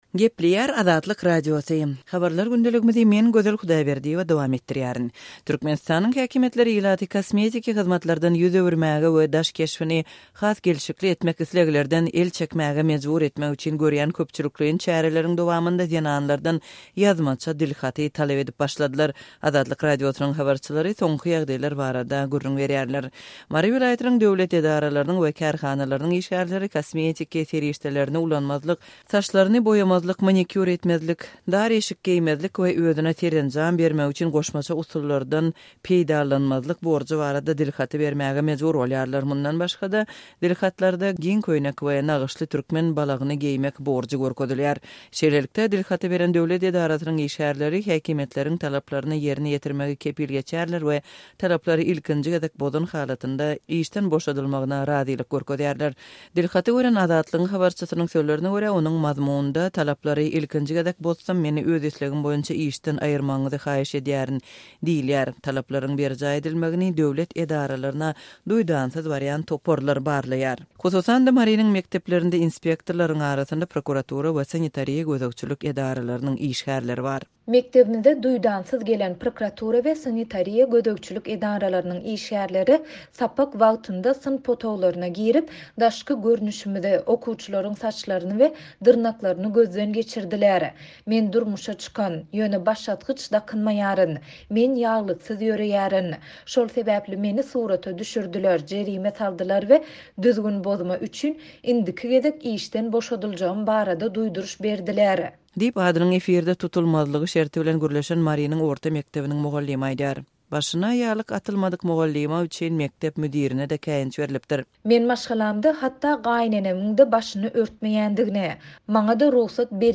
Türkmenistanyň häkimiýetleri ilaty kosmetiki hyzmatlardan el çekmäge we daş keşbini has gelşikli etmek isleglerden ýüz öwürmäge mejbur etmek üçin görýän köpçülikleýin çäreleriň dowamynda zenanlardan ýazmaça dilhaty talap edýärler. Azatlyk Radiosynyň habarçylary soňky ýagdaýlar barada gürrüň berýärler.